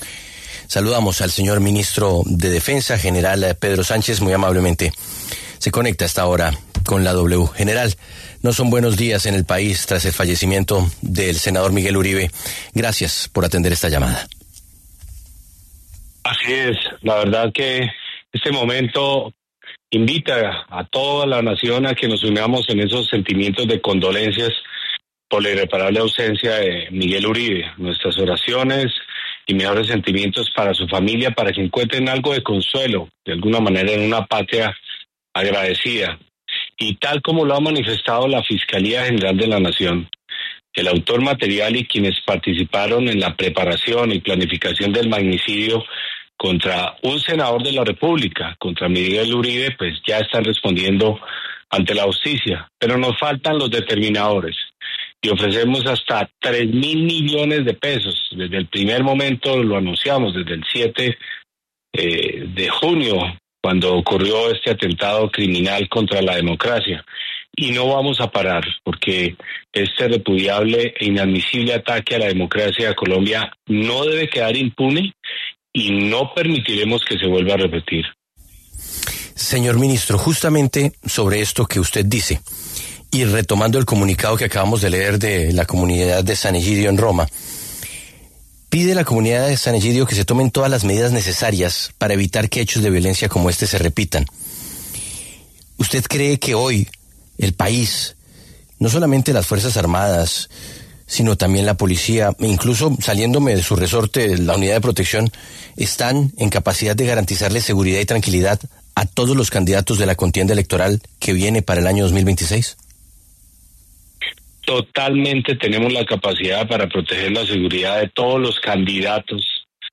El ministro de Defensa, Pedro Sánchez, pasó por los micrófonos de La W para hablar sobre la muerte del senador Miguel Uribe Turbay.